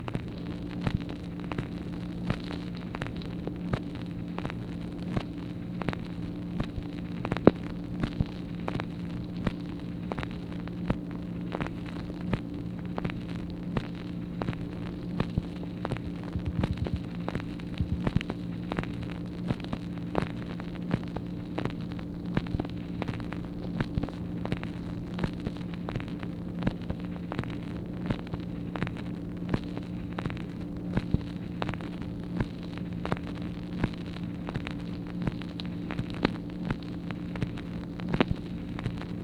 MACHINE NOISE, April 28, 1964
Secret White House Tapes | Lyndon B. Johnson Presidency